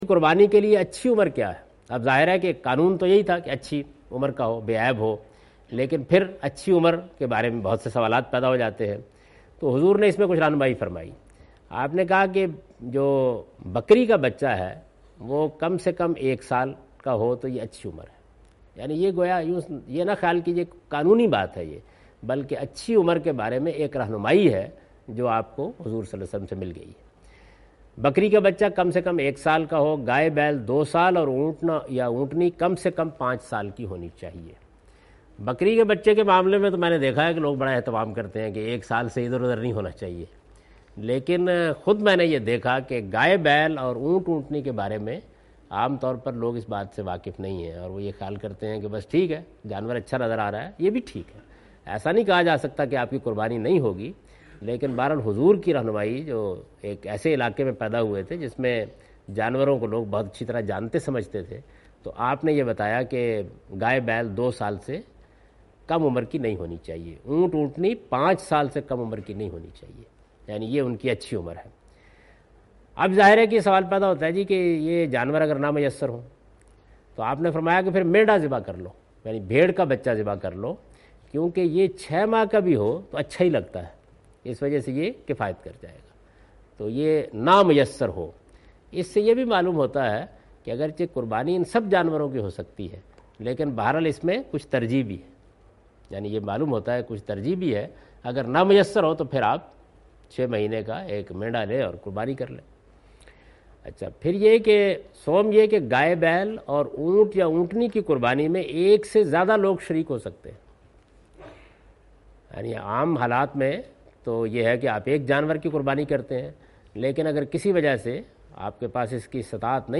In this video of Hajj and Umrah, Javed Ahmed Ghamdi is talking about "Right Age of Sacrifice Animal".
حج و عمرہ کی اس ویڈیو میں جناب جاوید احمد صاحب غامدی "جانور کی قربانی کی درست عمر کیا ہے؟" سے متعلق گفتگو کر رہے ہیں۔